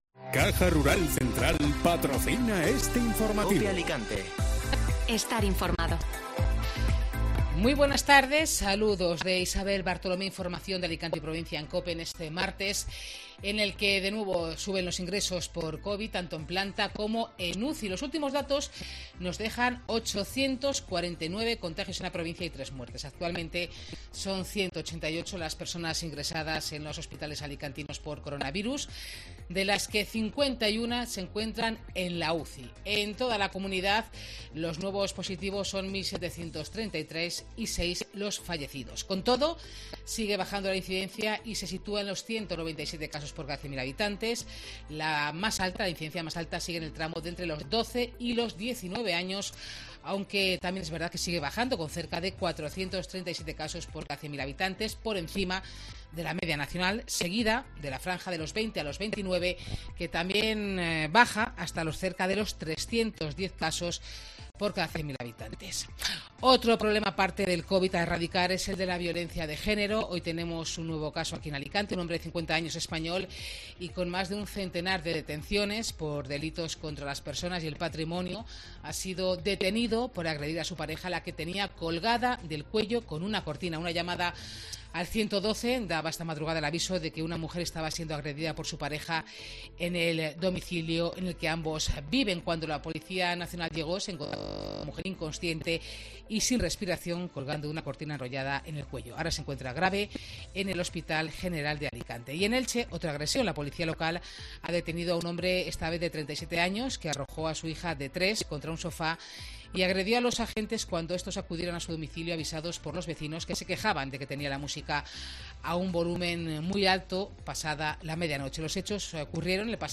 Informativo Mediodía COPE Alicante (Martes 31 de agosto)